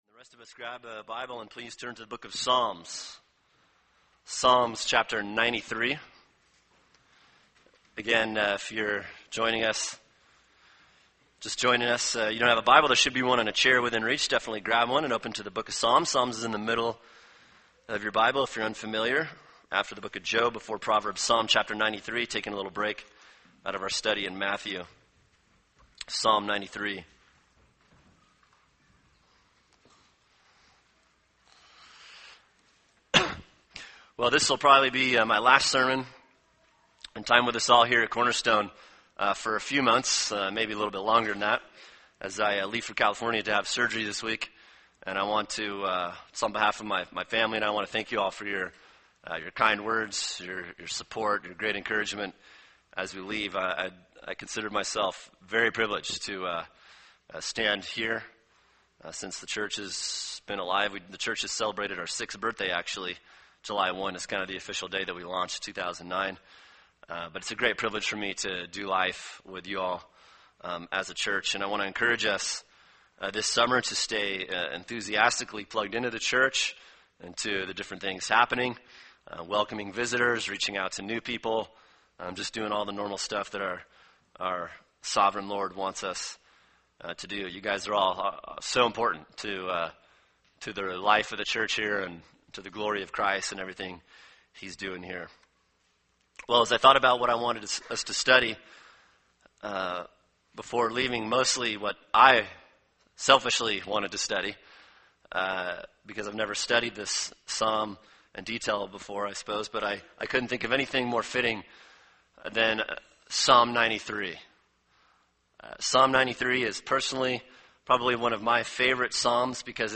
[sermon] Psalm 93 “The Supremacy of God” | Cornerstone Church - Jackson Hole